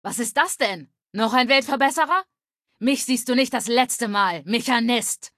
Datei:Femaleadult01default ms02 flee 000ab2d9.ogg
Fallout 3: Audiodialoge